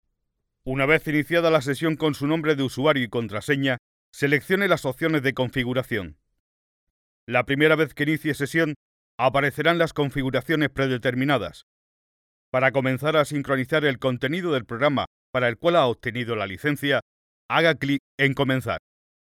español neutro masculino hombre personajes narrador documental comercial
Sprechprobe: eLearning (Muttersprache):